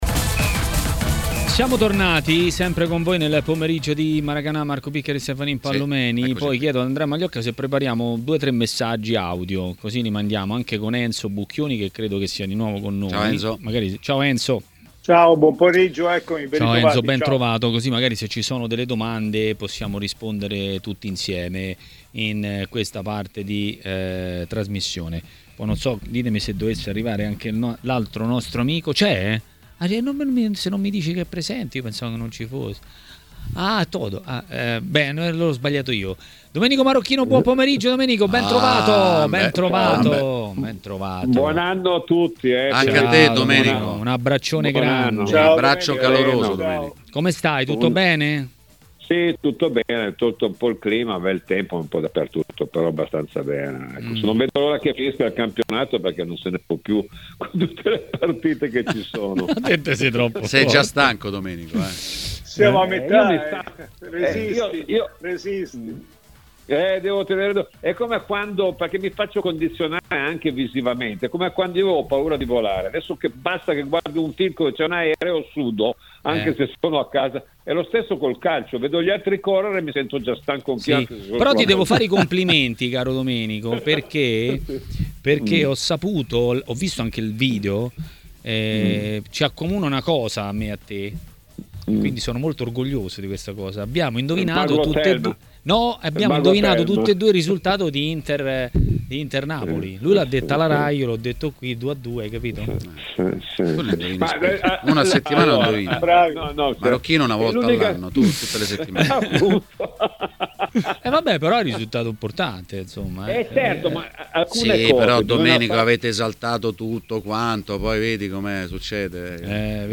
L'ex calciatore Domenico Marocchino è stato ospite di TMW Radio, durante Maracanà.